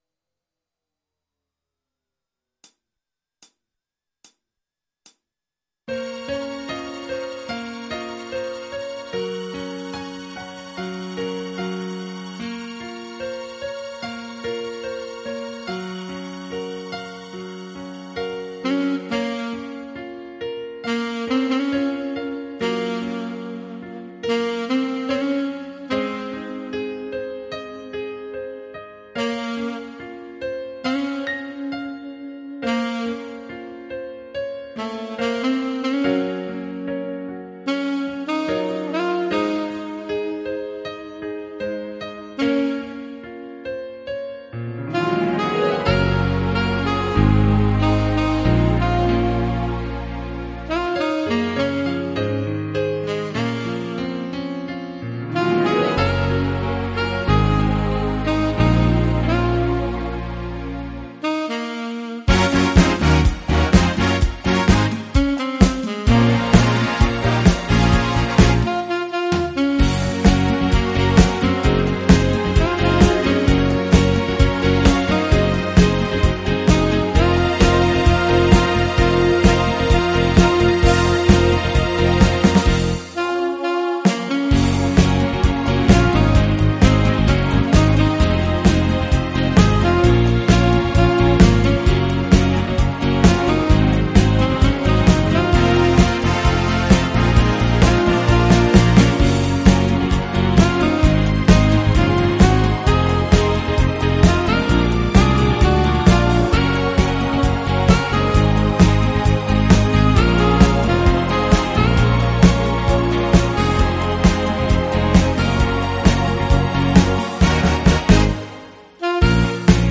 version instrumentale multipistes
au format MIDI Karaoke pro.